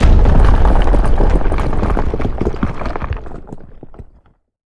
rock_avalanche_landslide_debris_01.wav